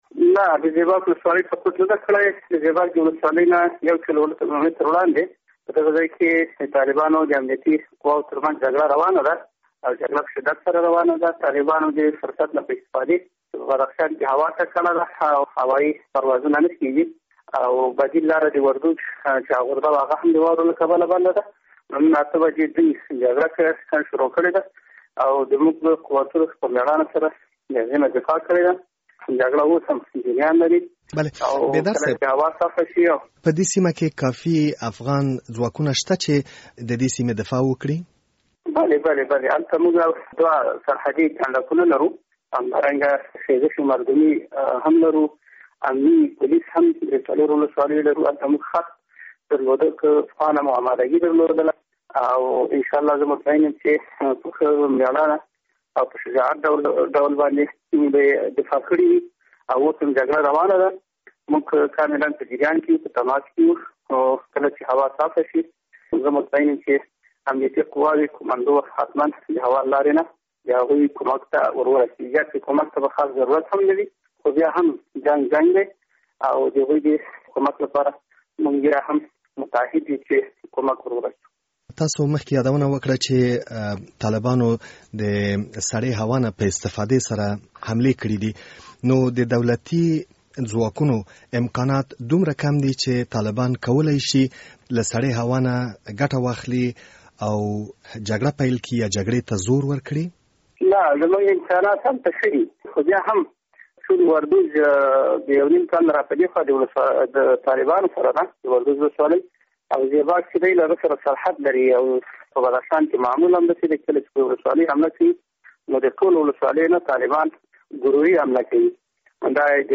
مرکه
له ګل محمد بیدار سره مرکه